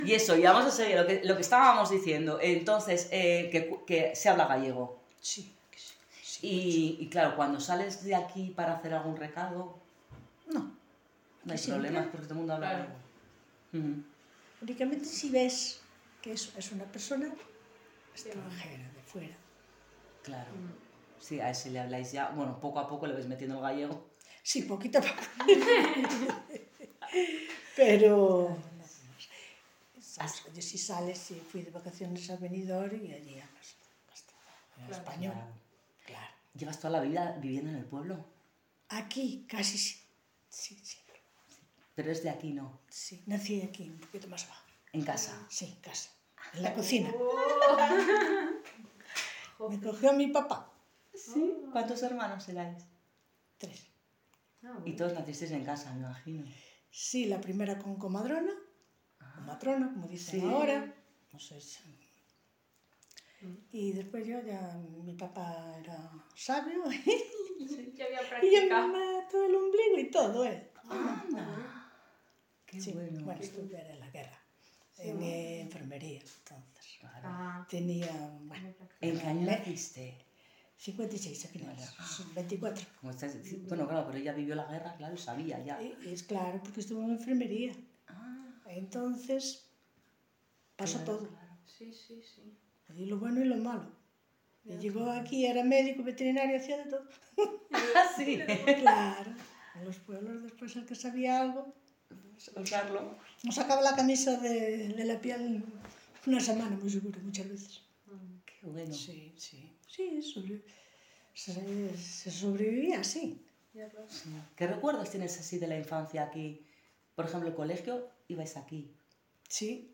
Enclave Miandreiras (Moldes - Bobor�s)
Encuesta